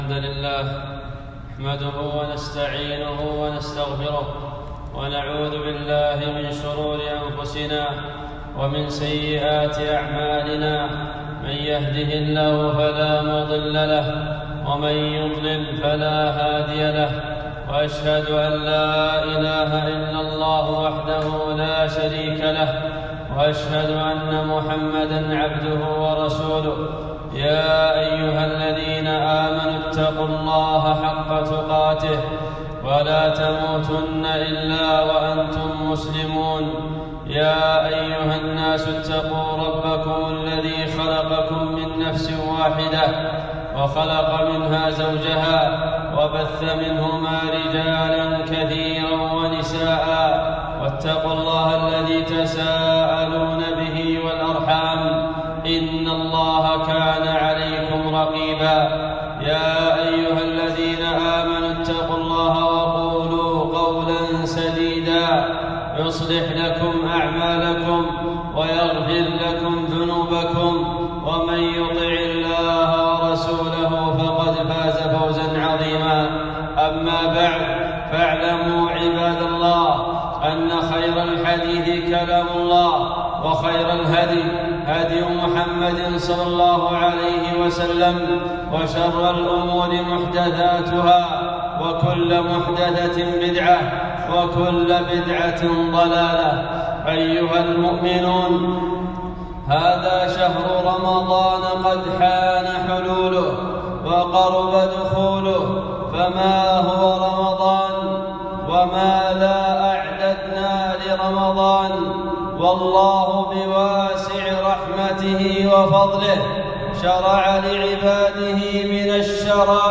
خطبة - كيف نستقبل رمضان ؟